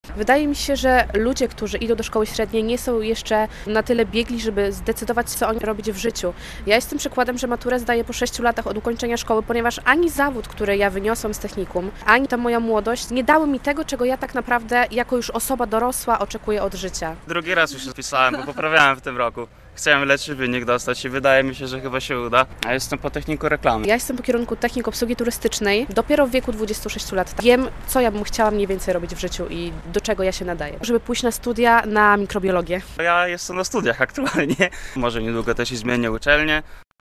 Z ponad 9 tys. tegorocznych podlaskich maturzystów, około 1200 ponownie przystępuje do egzaminu dojrzałości - relacja